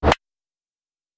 GrappleDetach.wav